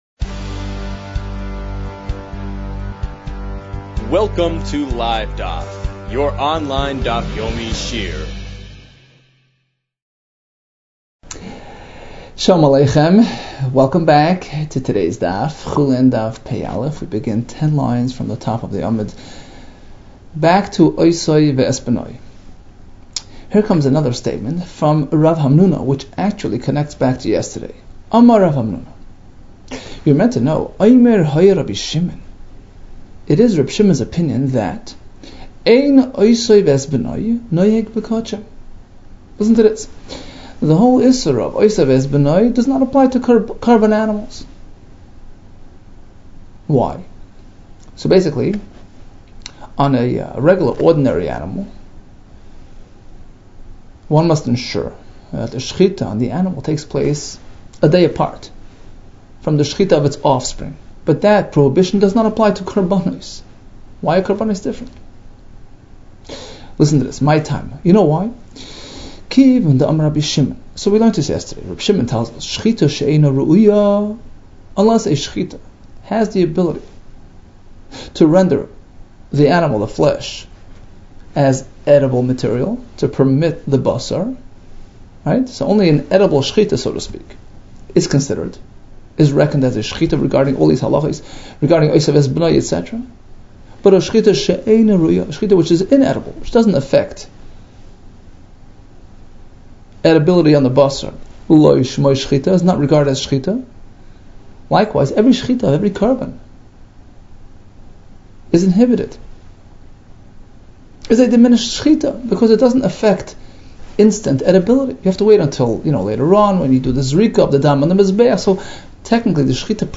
Chulin 80 - חולין פ | Daf Yomi Online Shiur | Livedaf